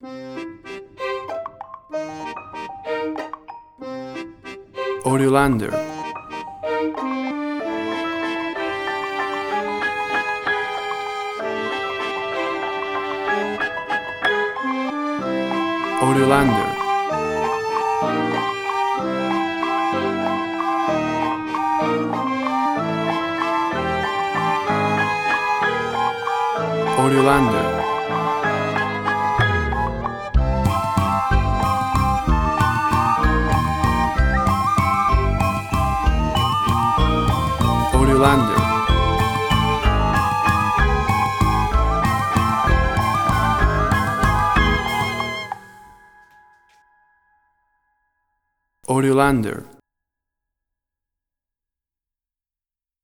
Orchestral and wird/fantastic valse
Tempo (BPM): 96